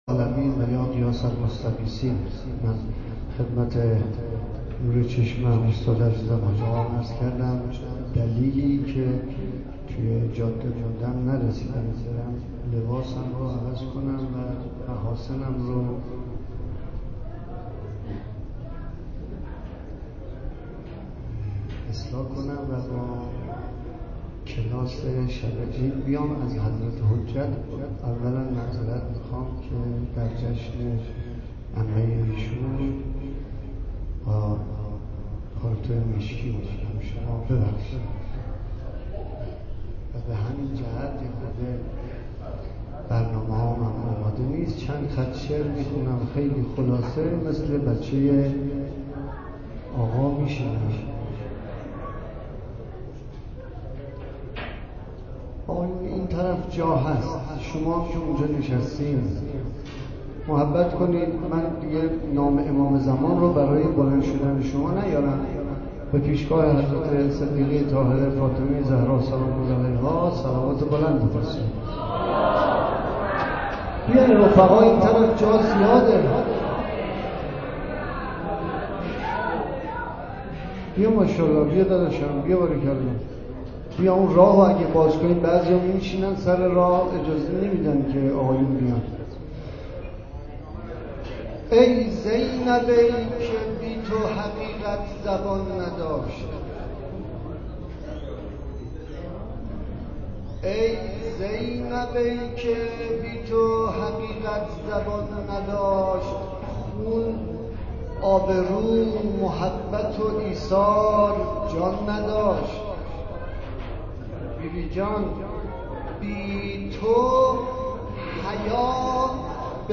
صوت مداحی در شب میلاد حضرت زینب(س) بیت الزهرا(س)